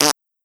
body_medium_impact_soft4.wav